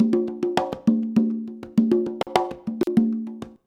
133CONGA04-L.wav